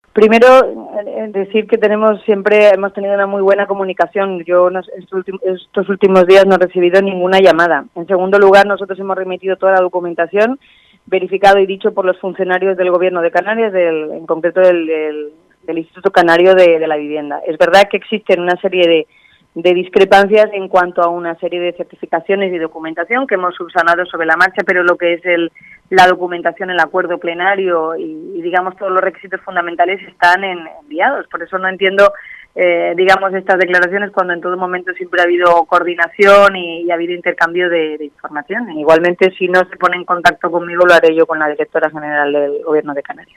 La alcaldesa de Arrecife, Eva de Anta, ha desmentido este jueves en Crónicas Radio-Cope Lanzarote a la directora general del Instituto Canario de la Vivienda (ICAV) del Gobierno de Canarias y ha garantizado que la capital ha completado el proceso de cesión de terrenos para construir 400 viviendas en Maneje.
eva_de_anta_-_viviendas_arrecife.mp3